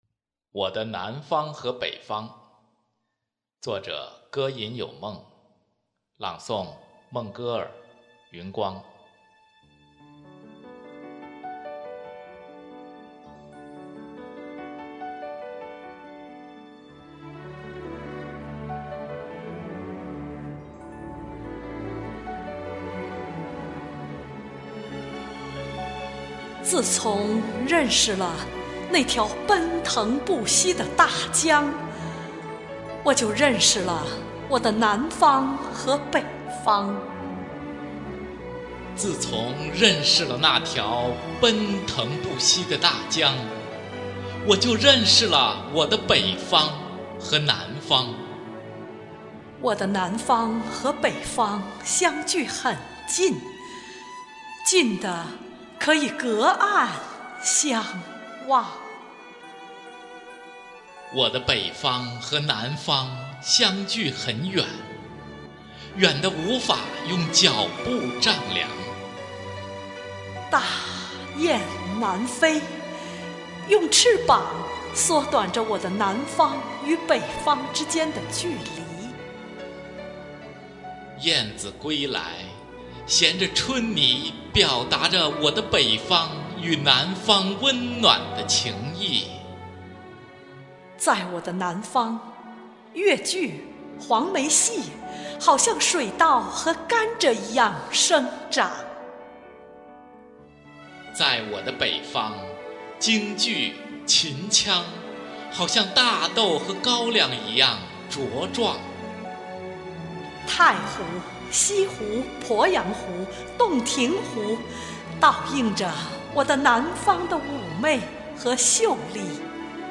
《我的南方和北方》朗诵